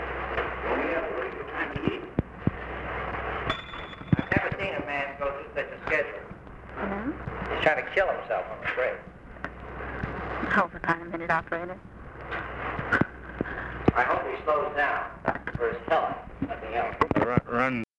OFFICE CONVERSATION, December 09, 1963